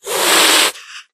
hiss2.ogg